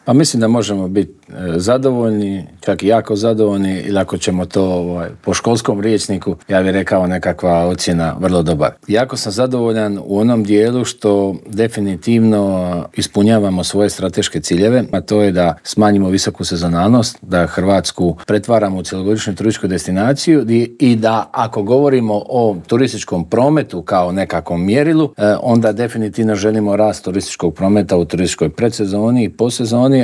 ZAGREB - Špica sezone je iza nas, a Hrvatska ponovno bilježi odlične turističke rezultate. Oduševila je predsezona, srpanj i kolovoz su bili na razini 2024. godine, a o svemu smo u Intervjuu tjedna Media servisa razgovarali s ministrom turizma i sporta Tončijem Glavinom.